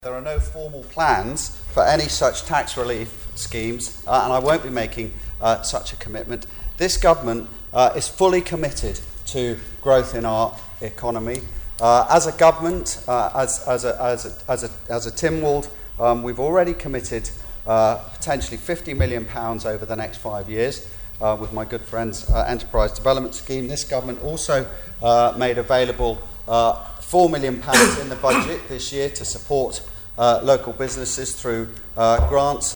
Yesterday in Tynwald, Treasury Minister Alf Cannan was quizzed on the issue by Ramsey MHK Lawrie Hooper.
But Mr Cannan said the government was already supporting local businesses: